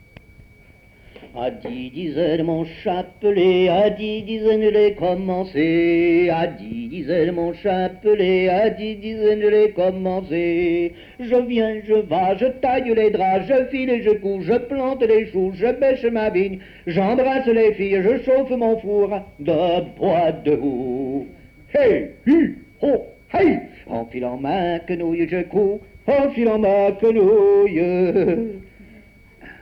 Enumératives - Nombres en décroissant
Chanson
Pièce musicale inédite